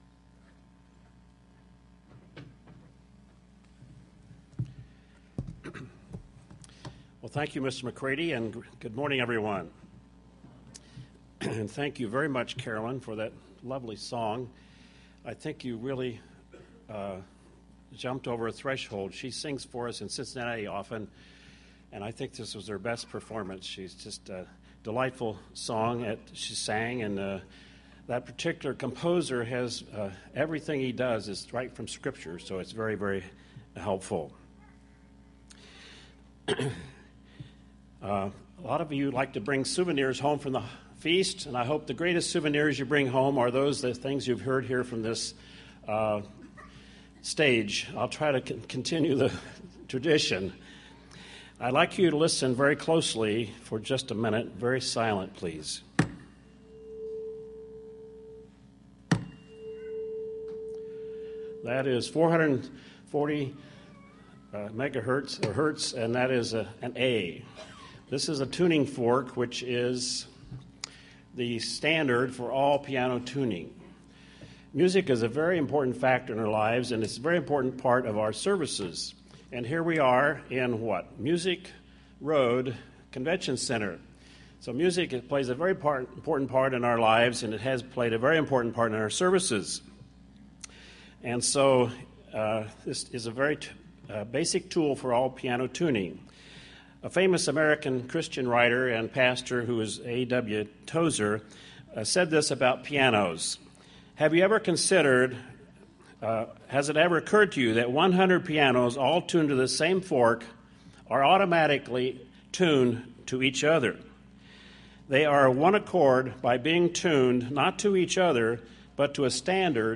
This sermon was given at the Pigeon Forge, Tennessee 2014 Feast site.